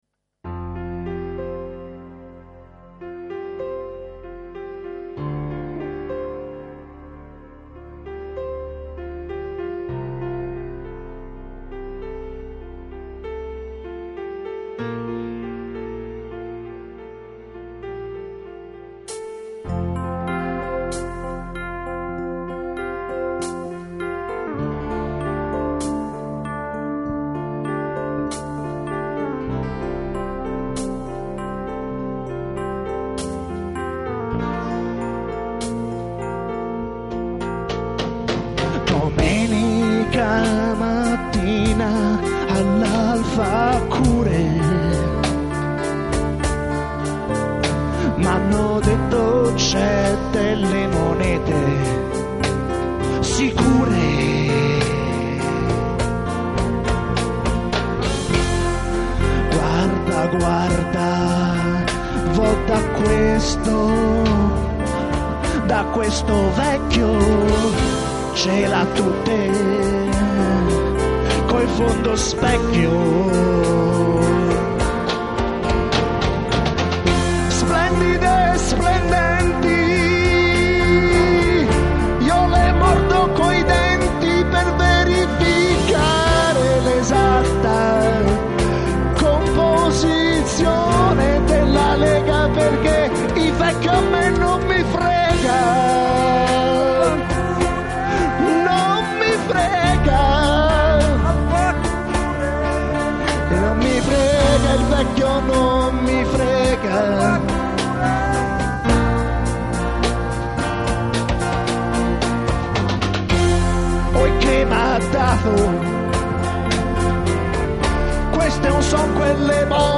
Questo brano e' stato assolutamente improvvisato, e si sente